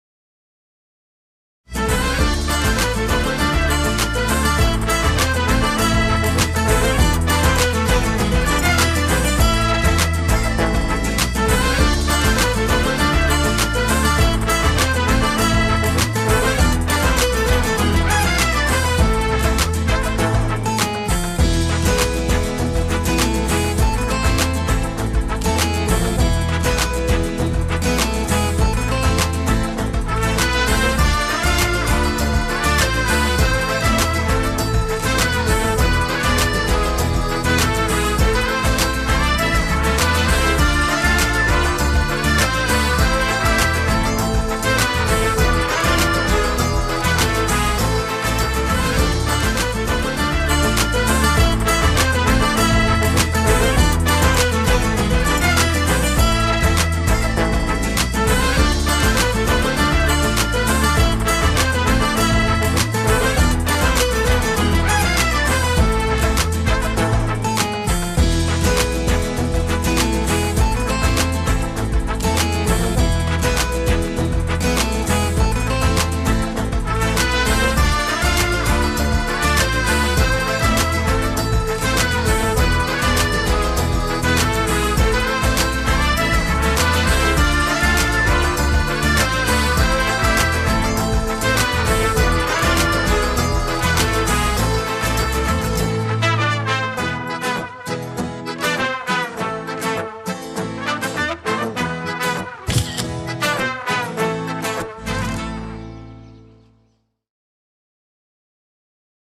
neşeli eğlenceli enerjik fon müziği.